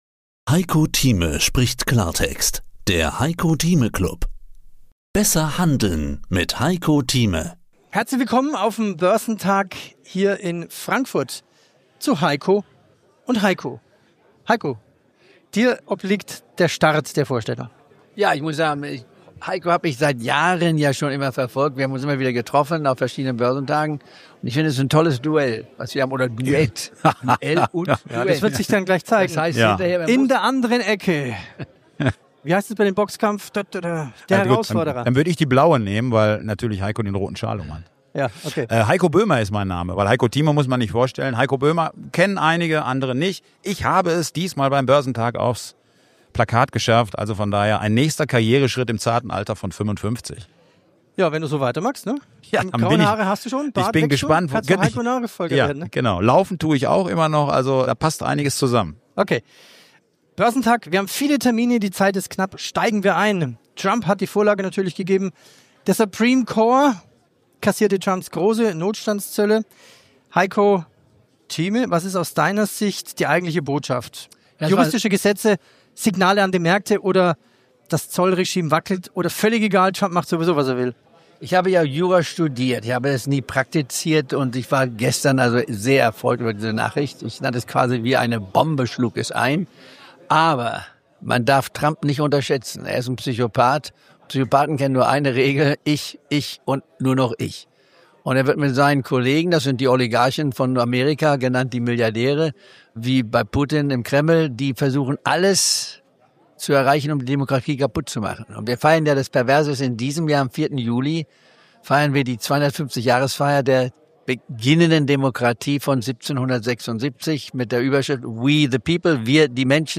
Neue Clubausgabe: Live vom Börsentag Frankfurt.